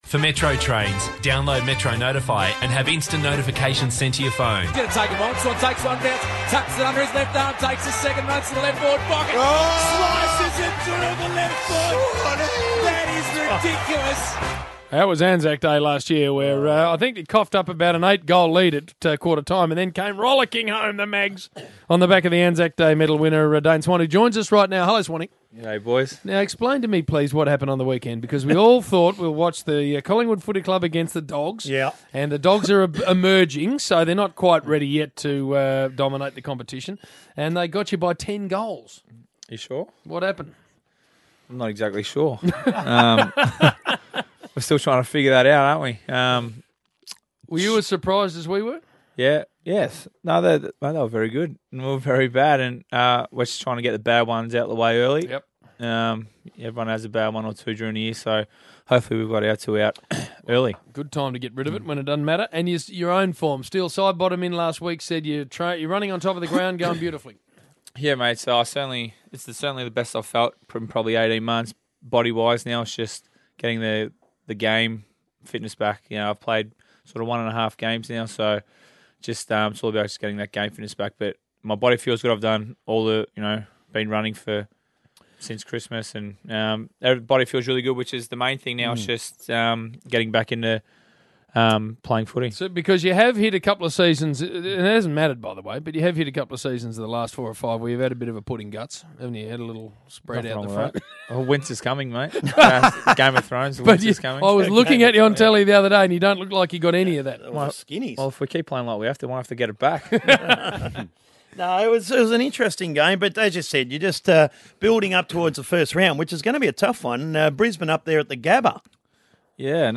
Listen to Collingwood champion Dane Swan chat with The Rush Hour's James Brayshaw and Bill Brownless on Triple M on Wednesday 25 March.